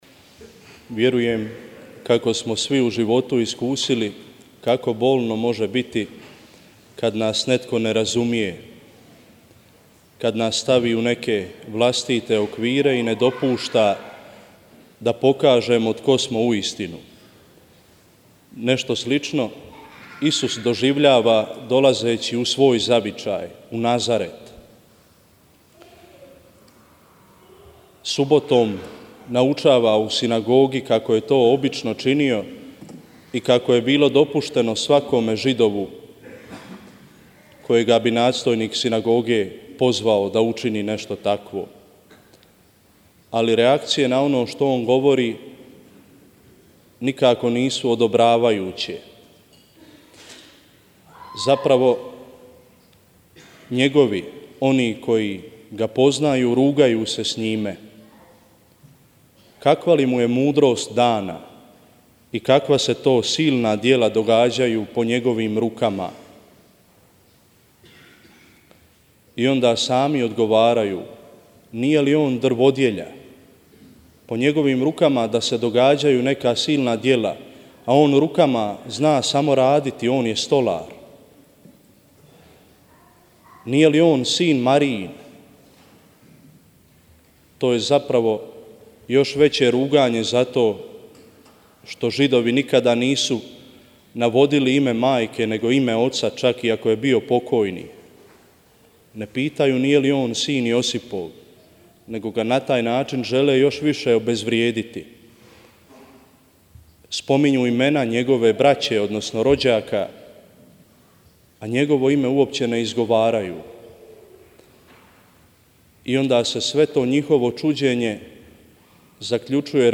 Duhovne obnova počela je prijavom sudionika, a nastavio se večernjim molitvenim programom, molitvom krunice i svetom misom u crkvi svetog Jakova u Međugorju